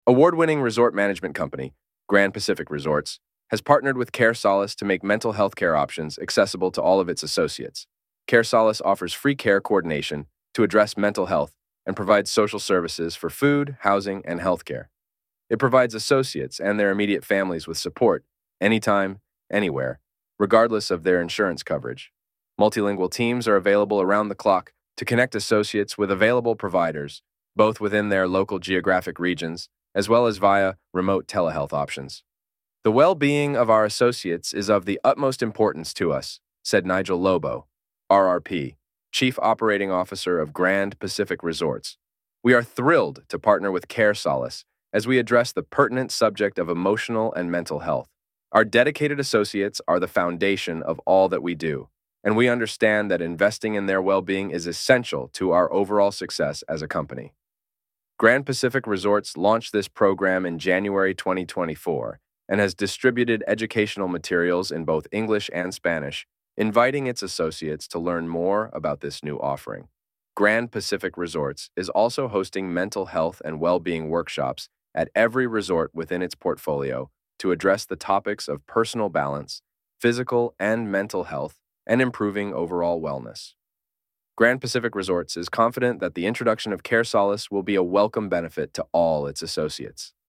CareSolace-AI-Voiceover.mp3